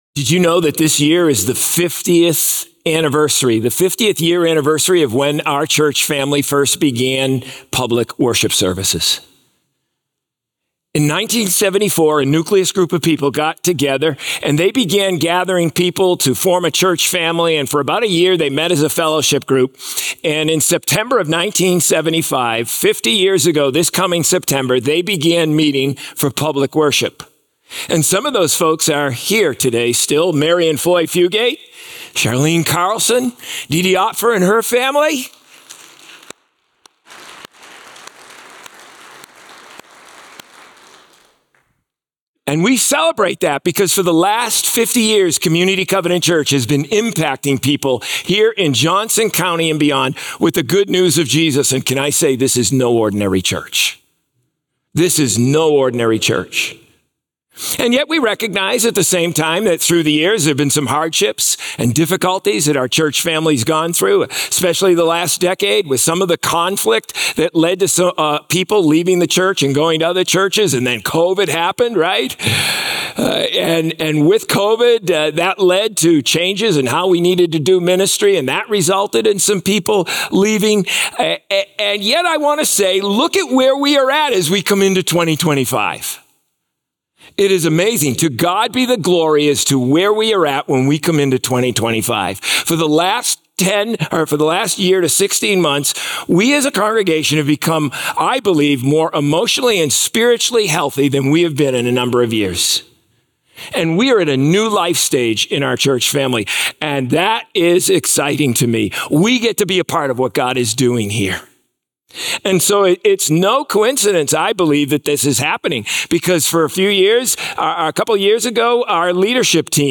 Sermons | COMMUNITY Covenant Church